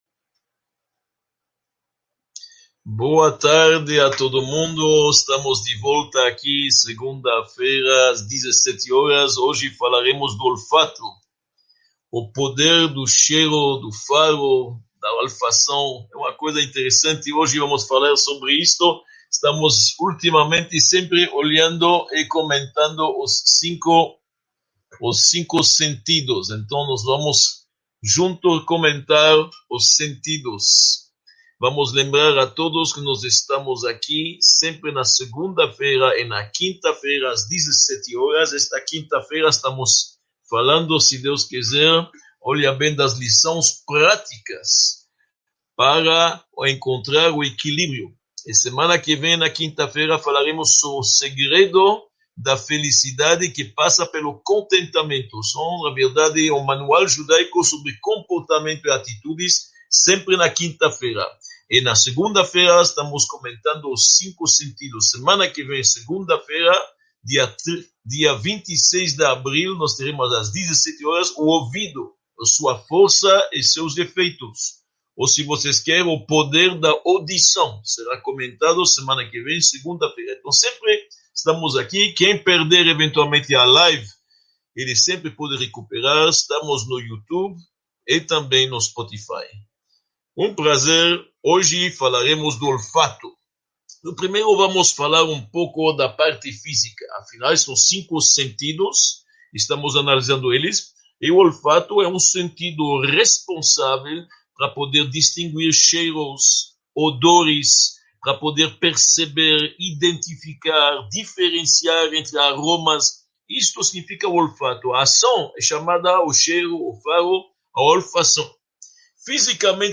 30 – Olfato: sua força e efeitos | Módulo I – Aula 30 | Manual Judaico